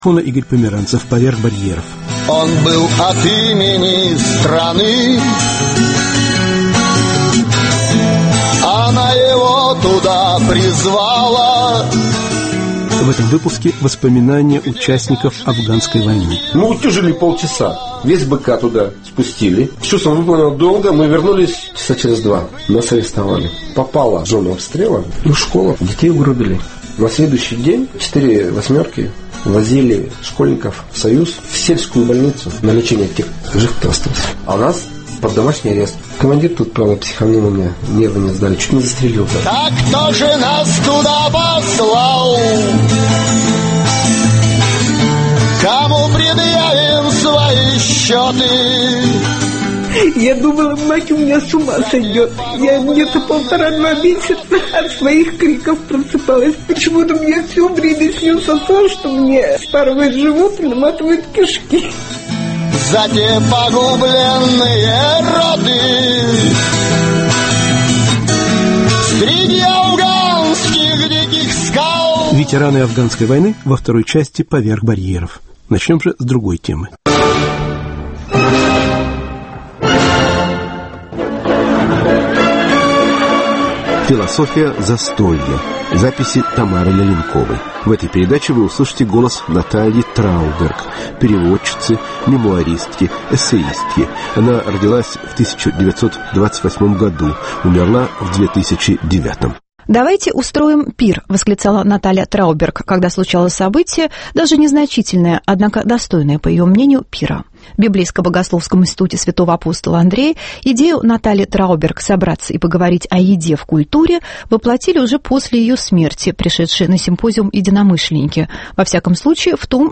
Разговор историков культуры и биолога о смысле застолья.